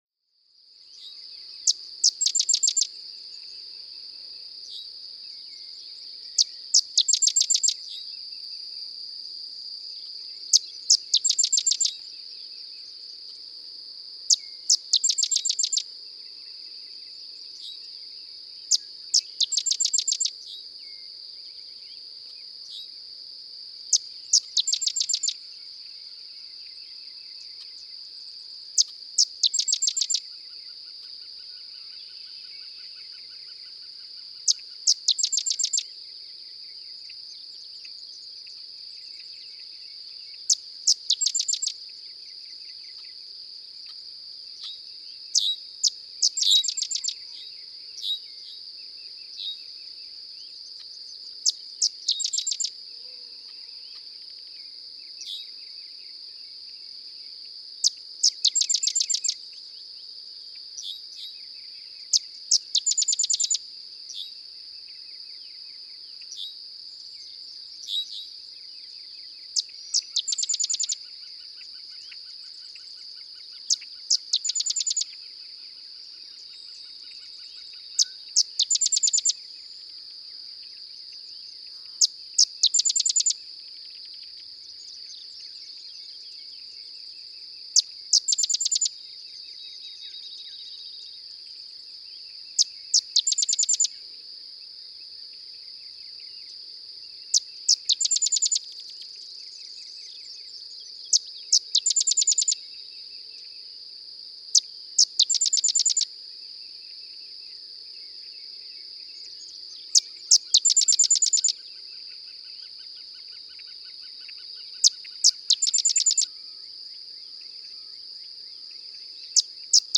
Sedge wren
♫586. Typical songs of the North American "species" of the sedge wren. Hear the switch to a new song at 1:00?
That's a new song tacked onto the end as well (at 3:40), after the calling red-winged blackbirds (hear the call matching among the blackbirds?).
Sebree, Kentucky.
586_Sedge_Wren.mp3